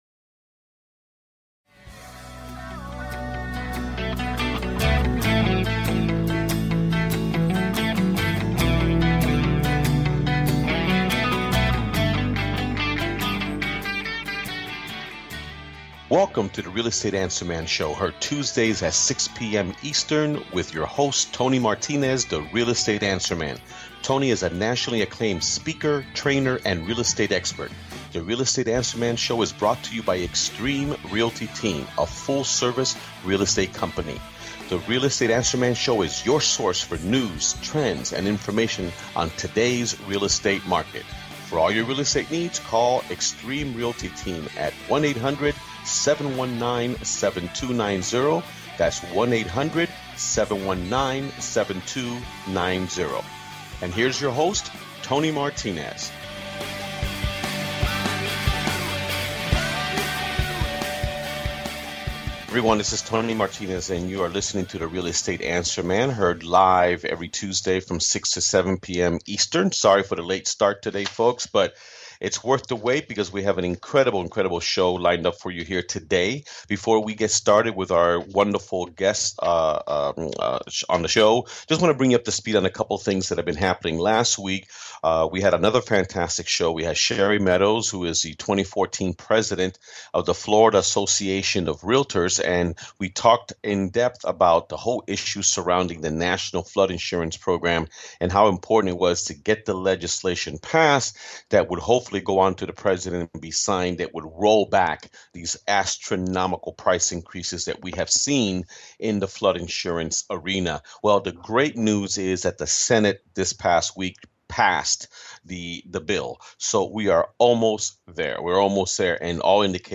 Talk Show Episode, Audio Podcast, Home Staging for Profit!!